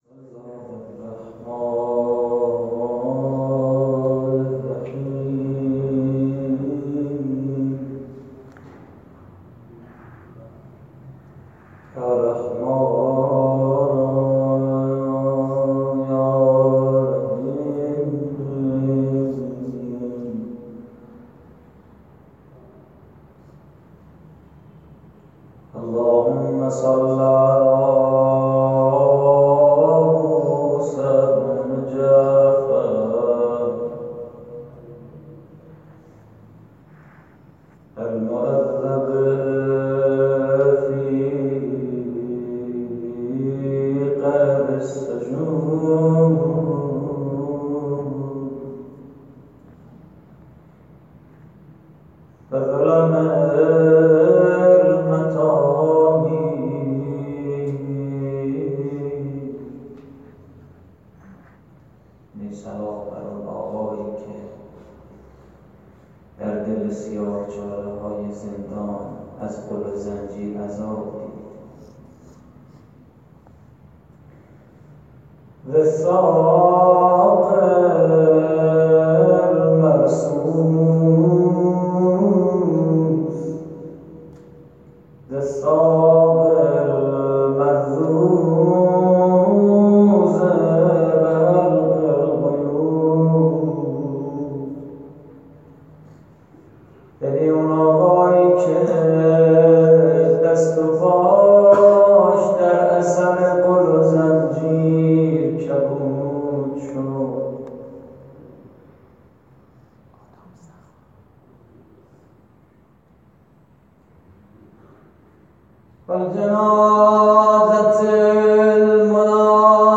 روضه: كمتر ای صیاد بر زندانی‌ات بیداد كن
مراسم عزاداری شهادت امام موسی کاظم (ع) / مسجد ولی الله اعظم (عج) – خیابان رجایی